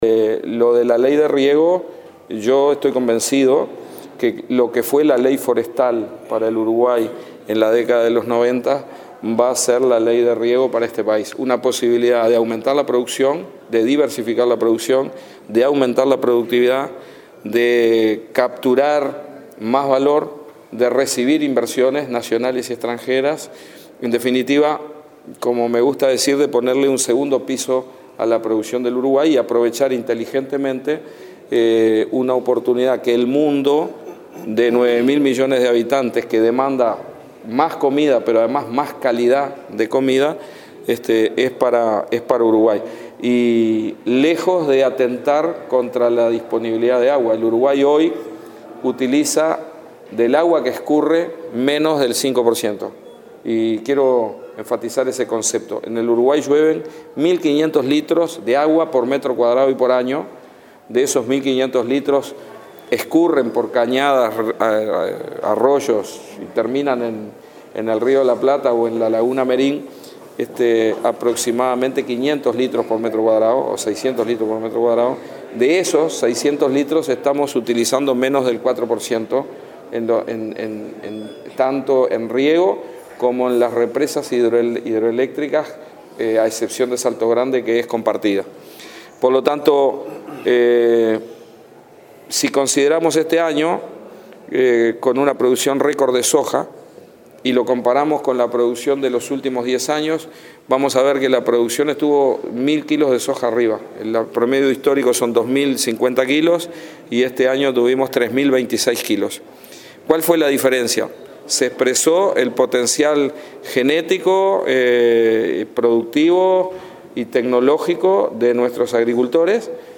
“El proyecto de ley sobre riego será lo que fue la ley forestal para Uruguay en la década del 90. Es una posibilidad de aumentar y diversificar la producción, aumentar la productividad y recibir inversiones nacionales y extranjeras”, subrayó el ministro de Ganadería, Tabaré Aguerre, en declaraciones a la prensa tras comparecer este martes 4 ante la Comisión de Presupuesto compuesta con Hacienda de Diputados.